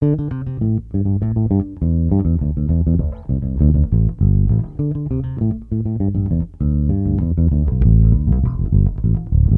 SONS ET LOOPS GRATUITS DE BASSES DANCE MUSIC 100bpm
Basse dance 11